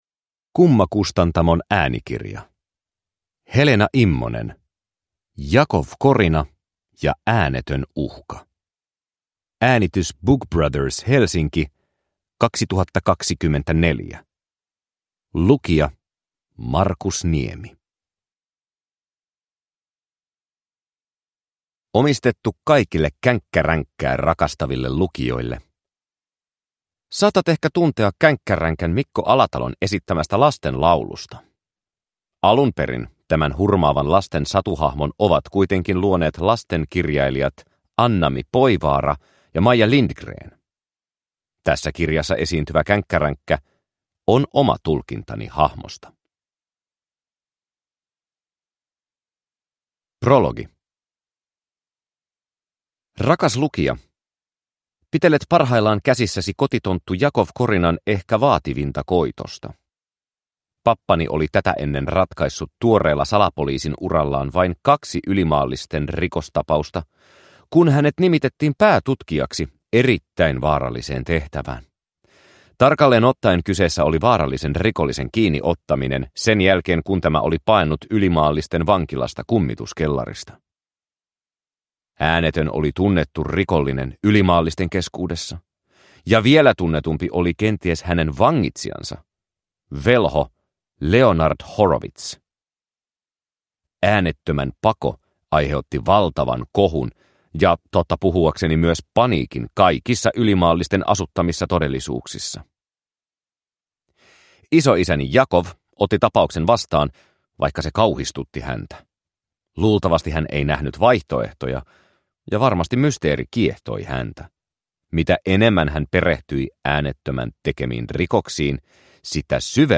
Jakov Korina ja äänetön uhka – Ljudbok